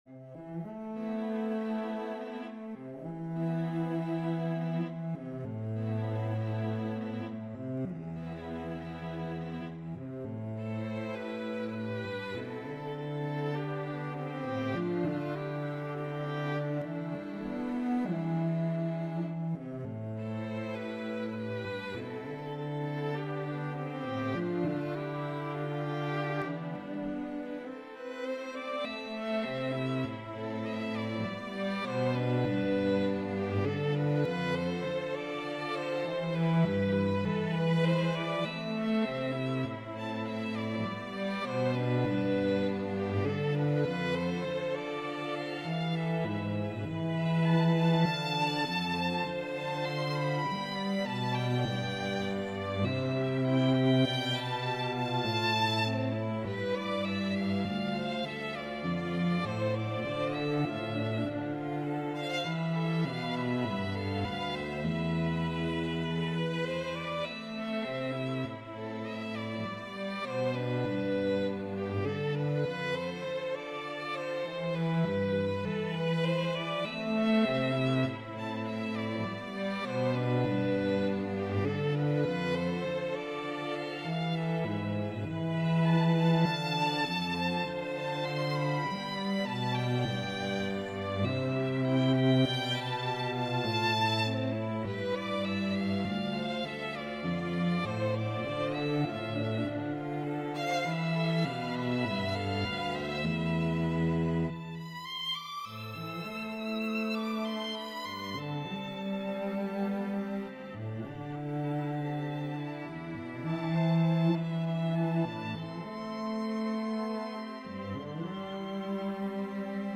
String Quartet
Worship/Spiritual